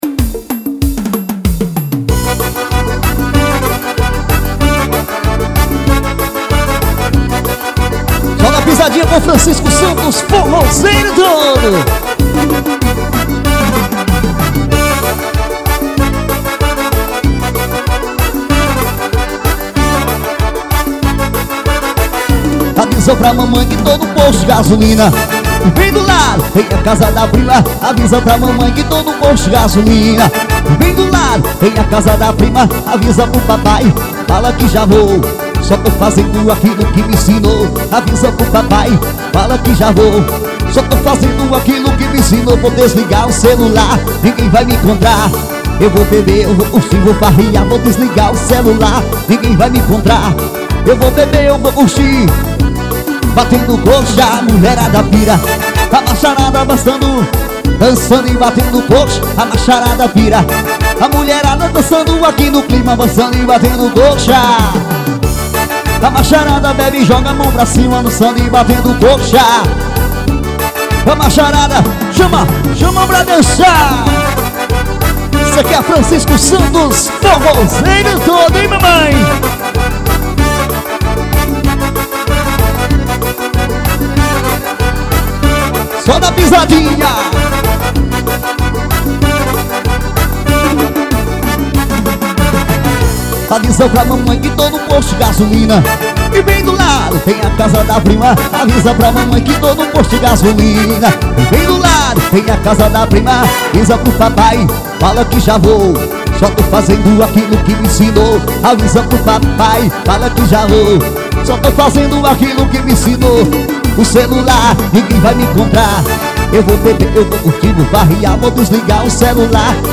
AOVIVO EM INHUMA-PI.